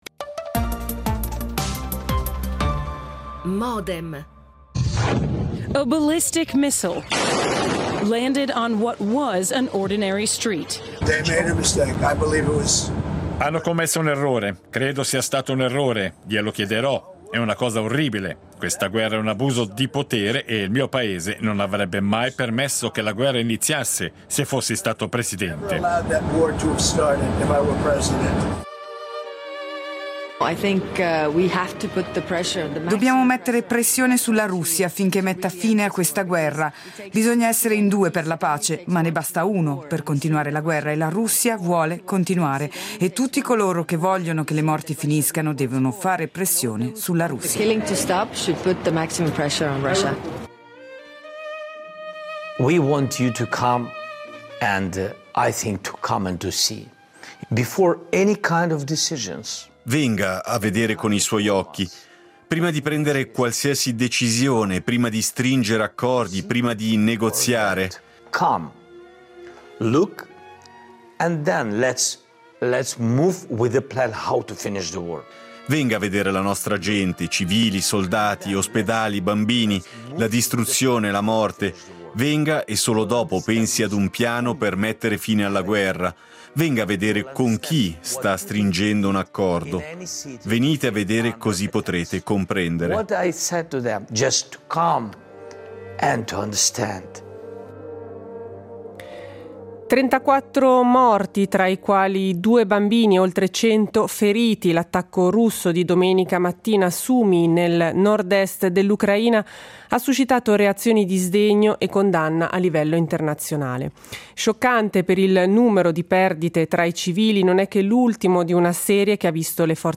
Modem, appuntamento quotidiano (dal lunedì al venerdì) in onda dal 2000, dedicato ai principali temi d’attualità, che vengono analizzati, approfonditi e contestualizzati principalmente attraverso l’apporto ed il confronto di ospiti in diretta.